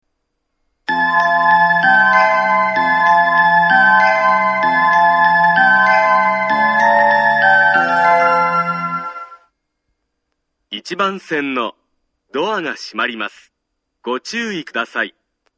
１番線発車メロディー 曲は「あざみ野」です。